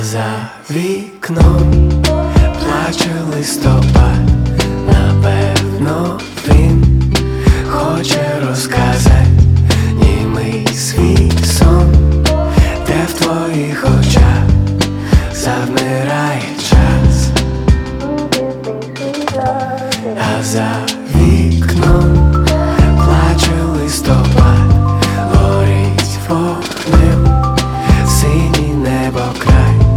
Indie Pop Alternative